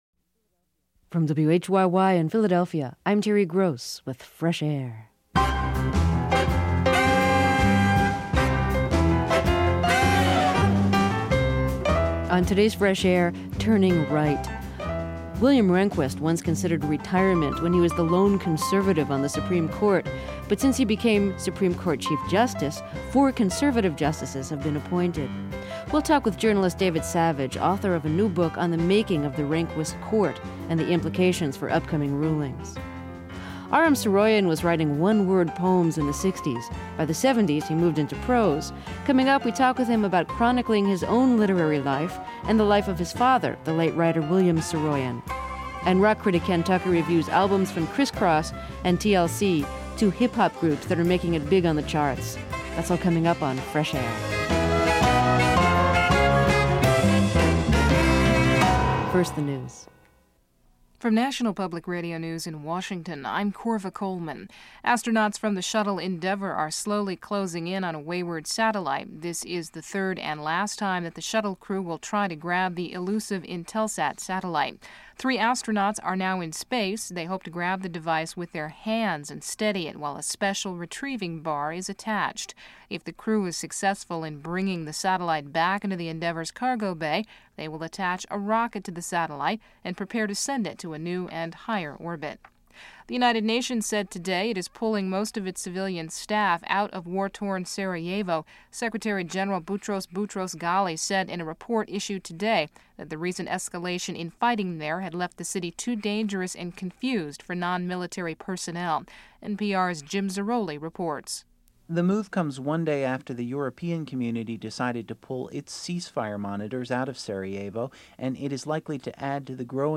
Interview Jane Mayer